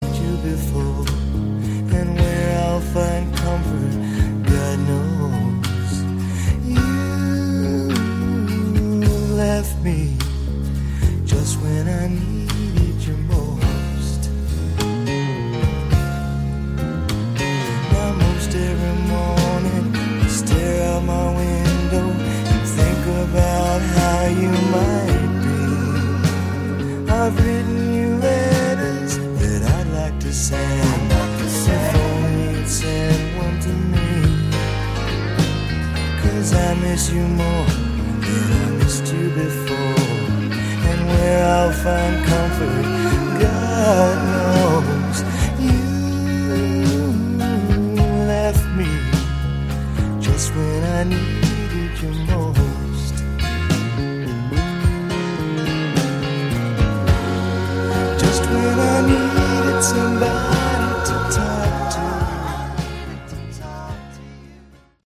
Category: Classic Hard Rock